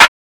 pbs - accent [ Snare ].wav